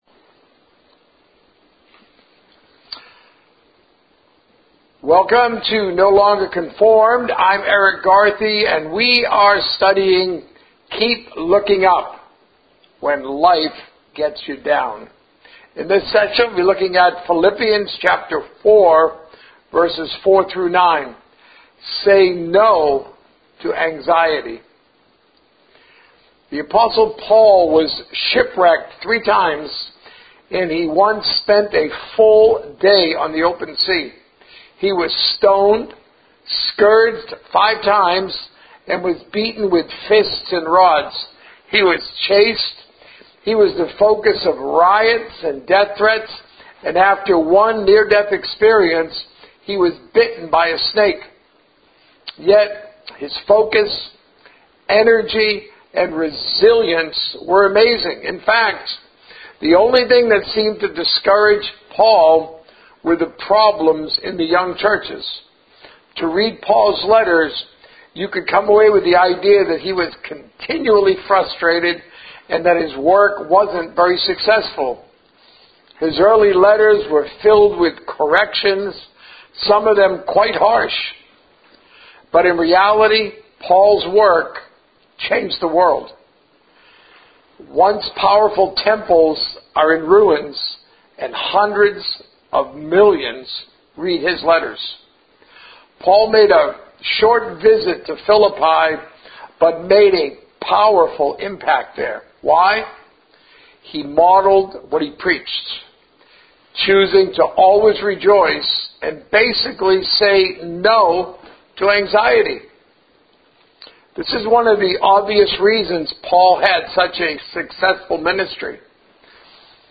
A message from the series "Keep Looking Up."